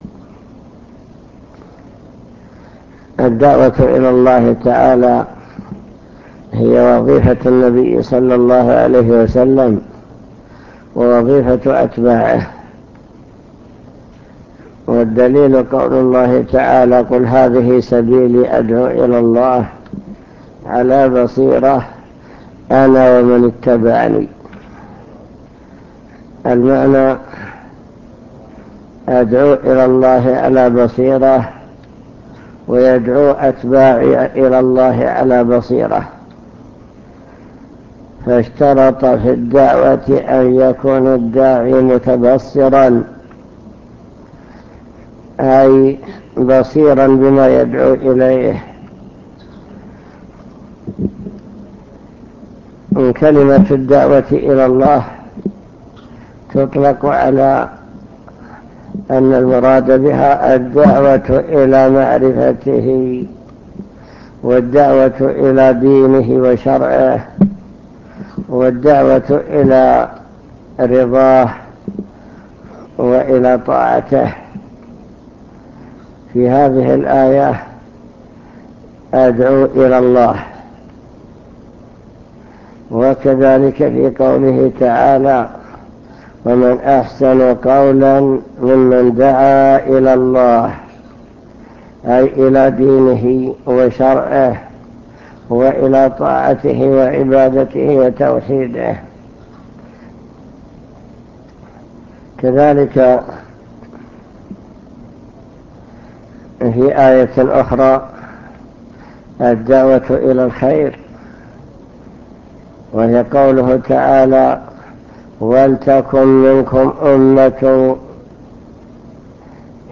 المكتبة الصوتية  تسجيلات - لقاءات  لقاء مع الشيخ بمكتب الجاليات